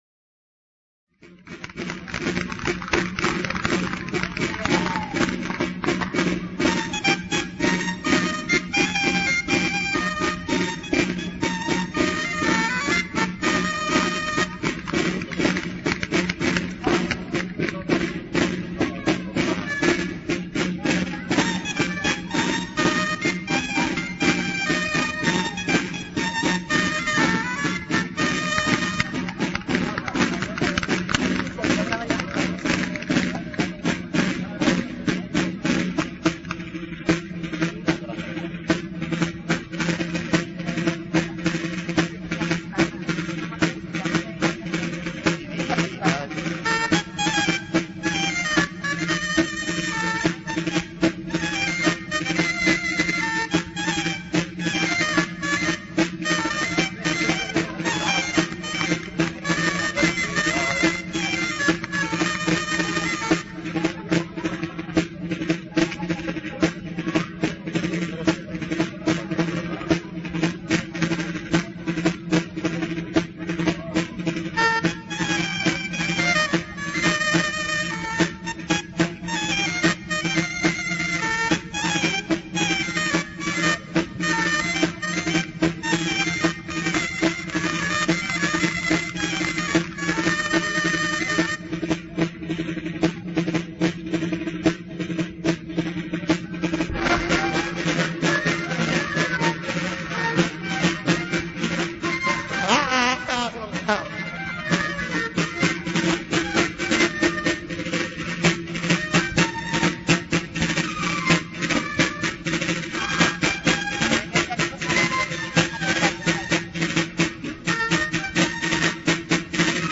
VILLARLUENGO
El baile del reinau.- En el vídeo se representa el reinau por los habitantes del pueblo.